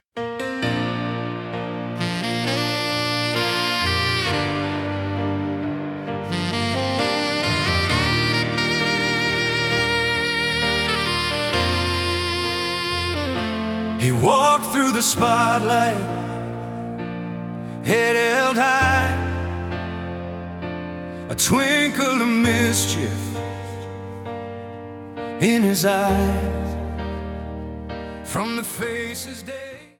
ballad version